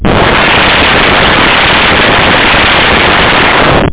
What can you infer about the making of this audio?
Amiga 8-bit Sampled Voice